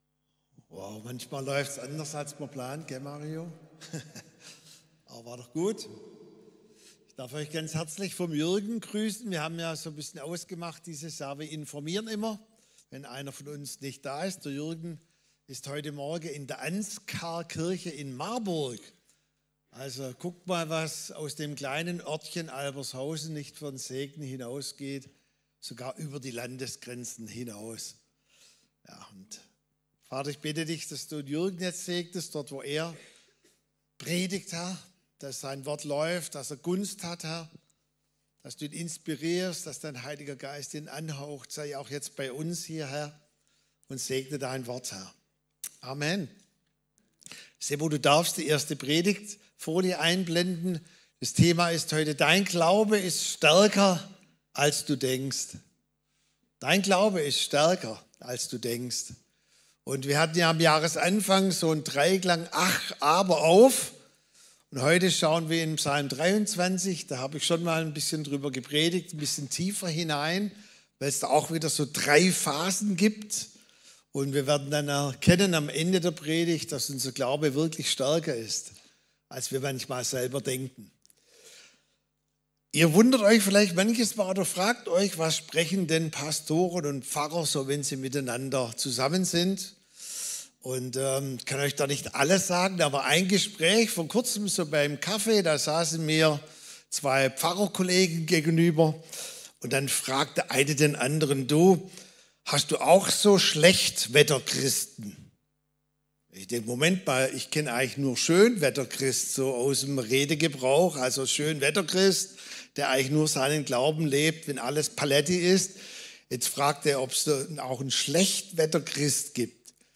Sonntagspredigten